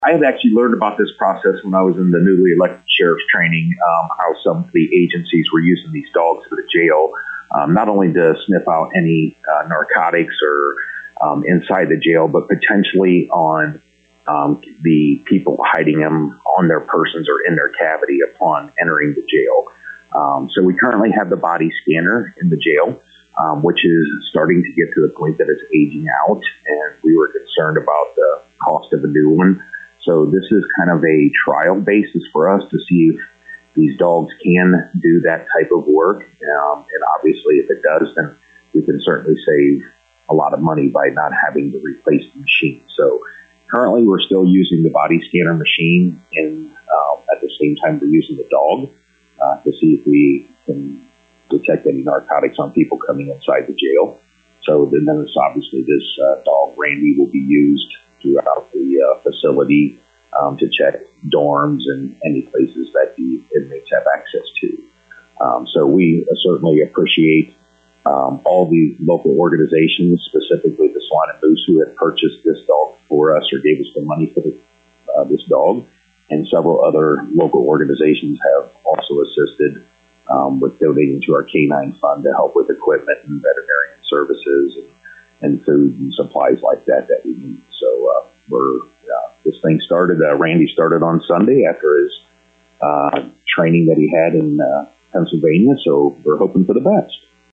To hear comments from Sheriff Doug Timmerman: